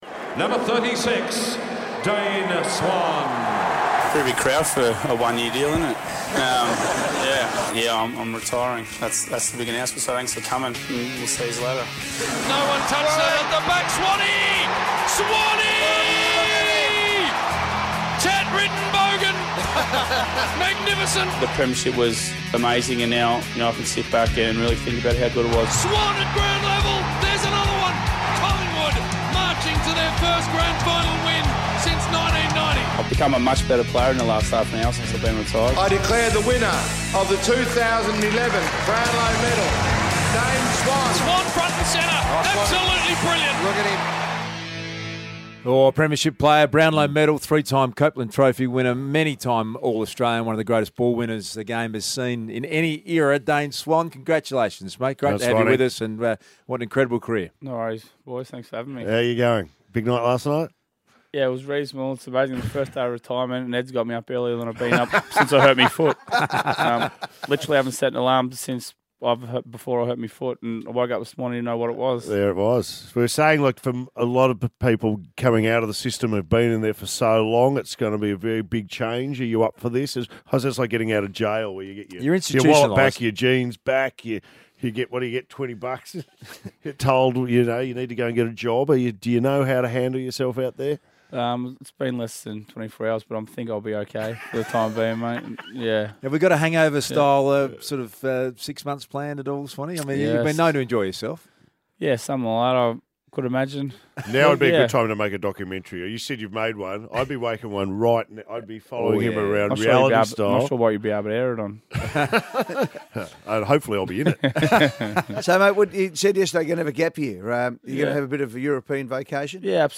Listen as Dane Swan joins the Triple M Hot Breakfast team on the morning after his retirement from AFL football.